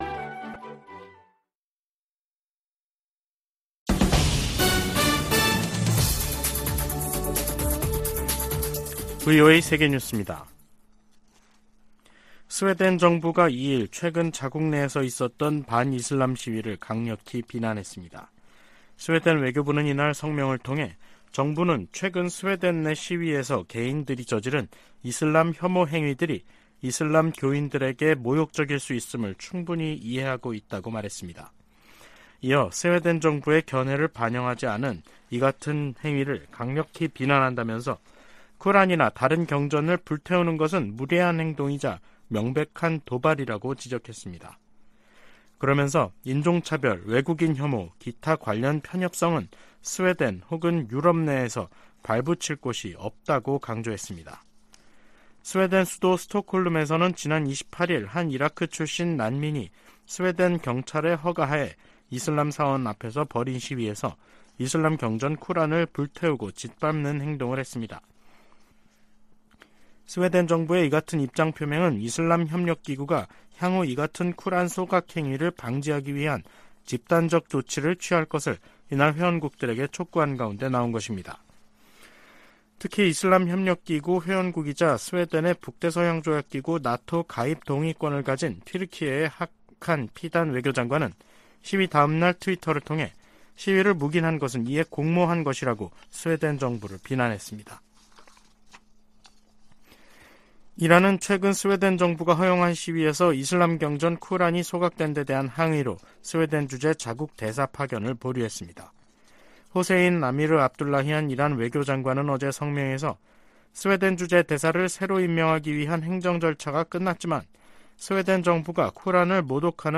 VOA 한국어 간판 뉴스 프로그램 '뉴스 투데이', 2023년 7월 3일 3부 방송입니다. 최근 미국 의회에서는 본토와 역내 미사일 방어망을 강화하려는 움직임이 나타나고 있습니다.